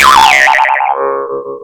Jaw Harp Sound Effect Free Download
Jaw Harp